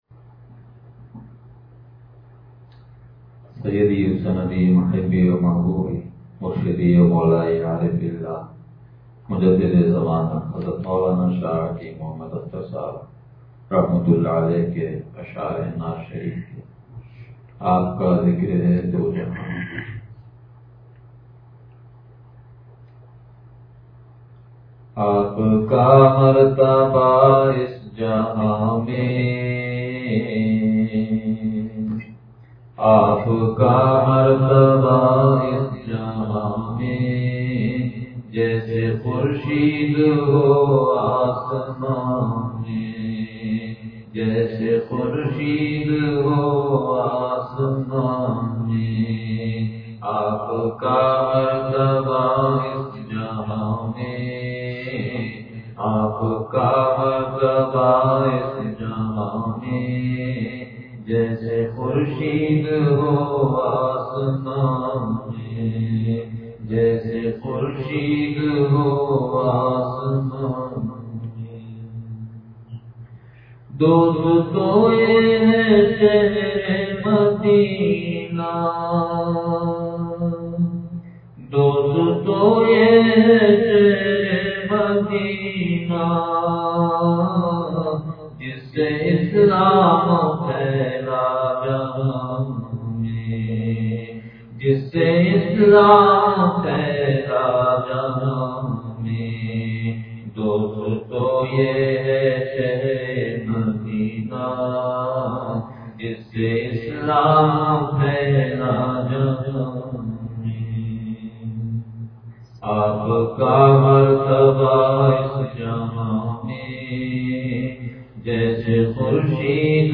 نعت شریف – آپ کا ذکر ہے دوجہاں میں – یہ آہ سحر کااثر دیکھتے ہیں – مجالس علم و حکمت